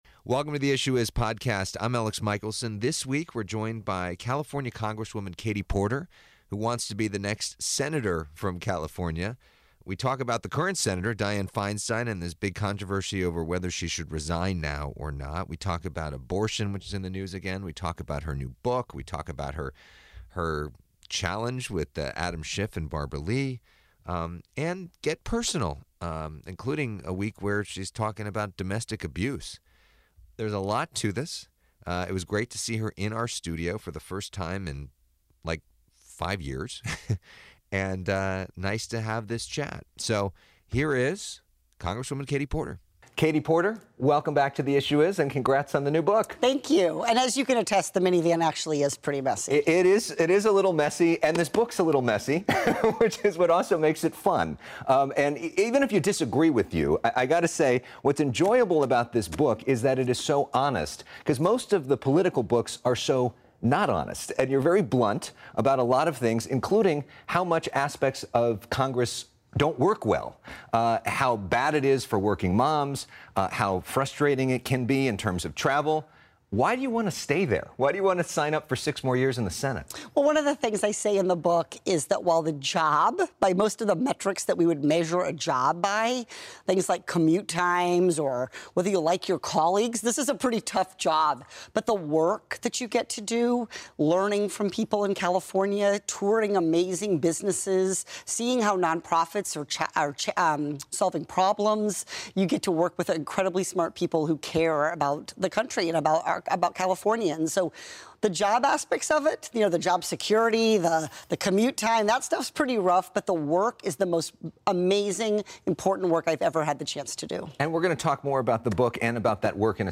This week, Rep. Katie Porter joins The Issue Is to discuss her push to move to the upper Congressional chamber in 2025, in addition to her new book.